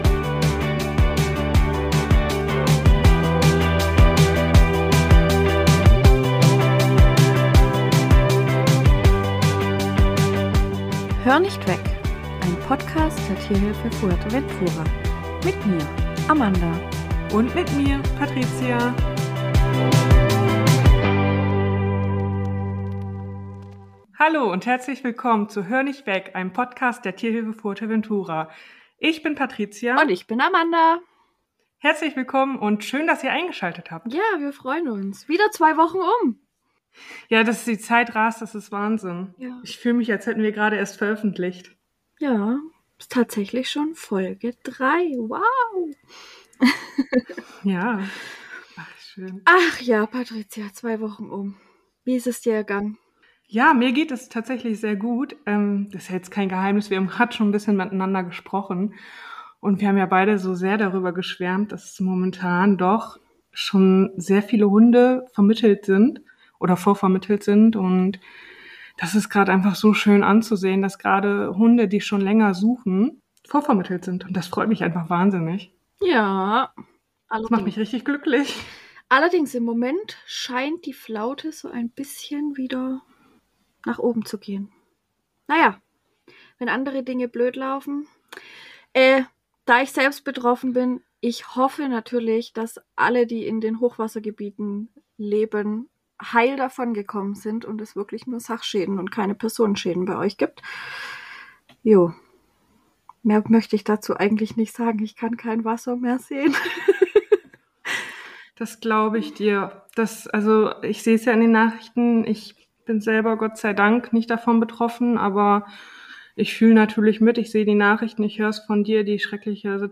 Music (Intro/Outro)